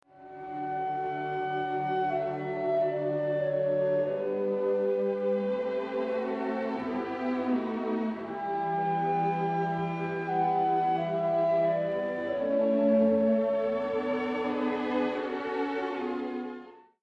Concerto pour clarinette
cct clarinet, MOZART.mp3